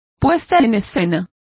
Complete with pronunciation of the translation of productions.